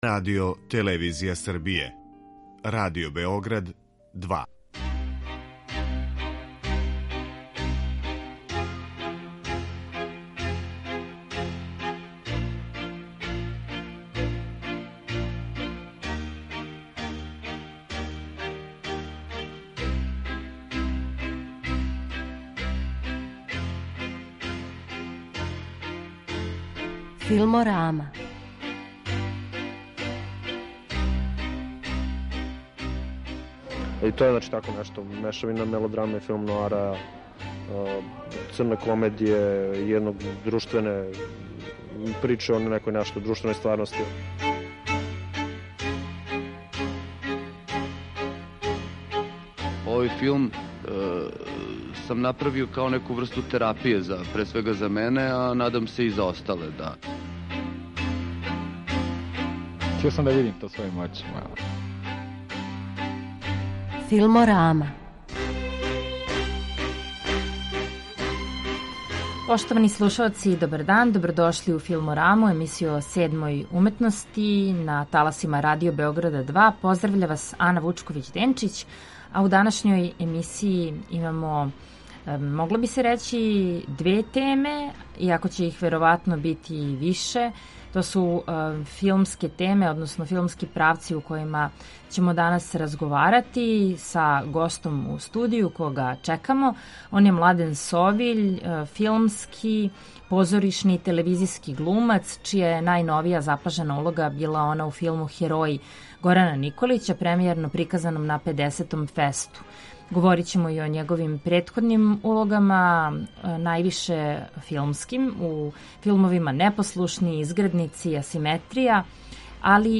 Чућемо разговор забележен после премијере филма, који ће се, како је планирано, у априлу приказивати у Дворани Kултурног центра.